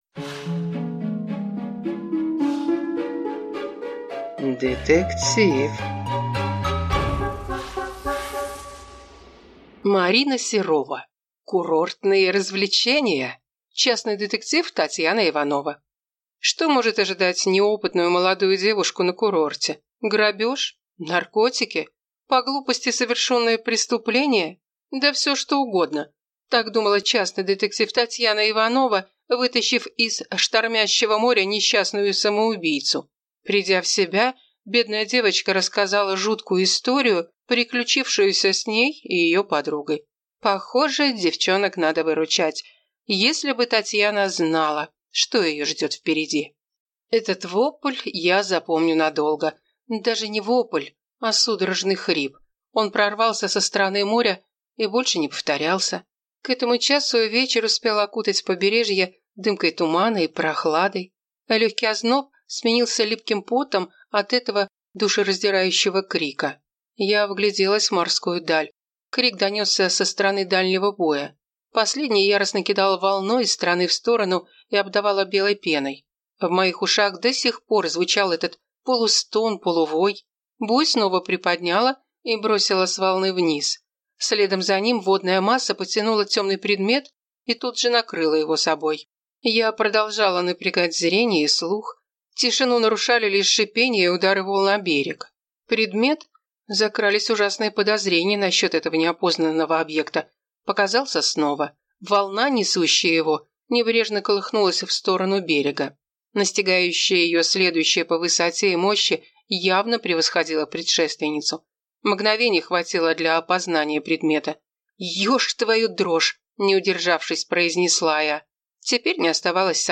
Aудиокнига Курортные развлечения